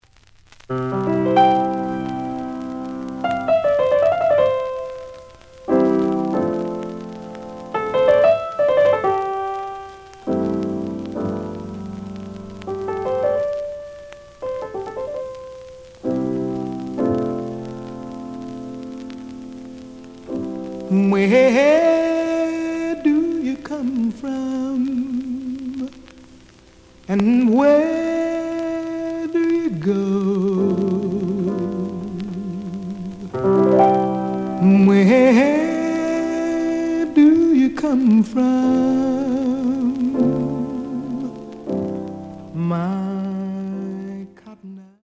The audio sample is recorded from the actual item.
●Format: 7 inch
●Genre: Vocal Jazz